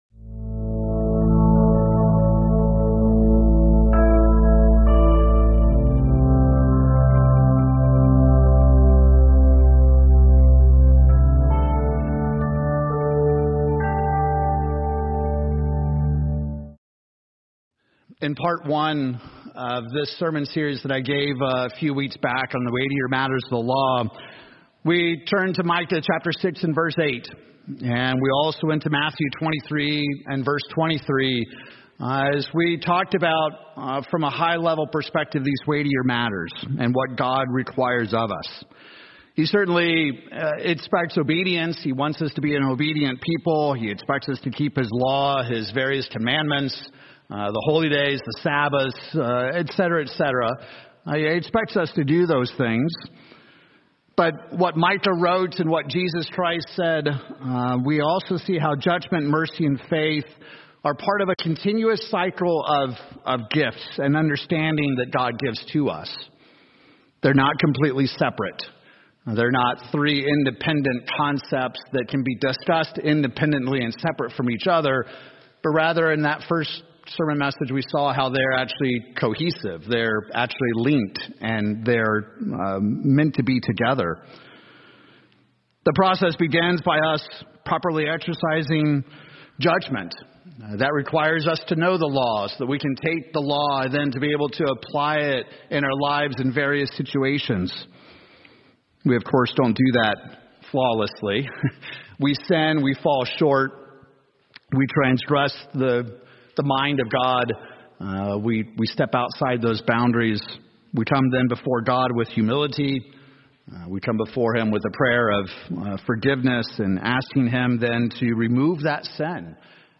Sermons
Given in Tulsa, OK Oklahoma City, OK